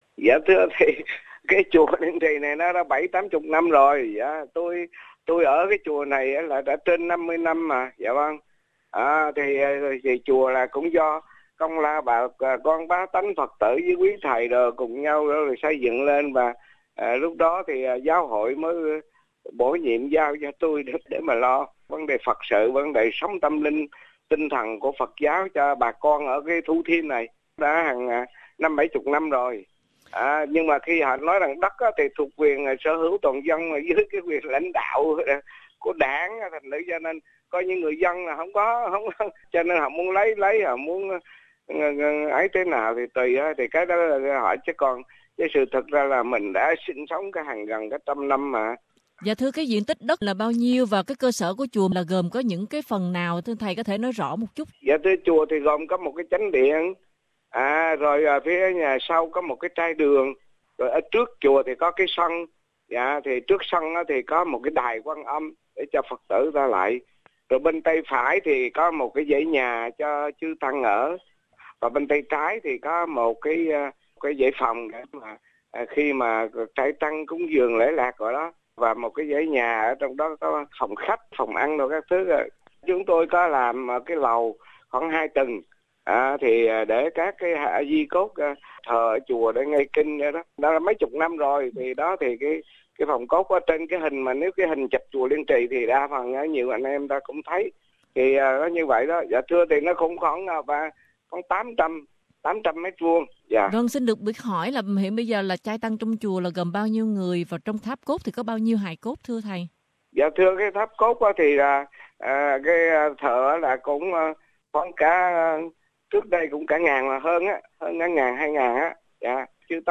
Trả lời phỏng vấn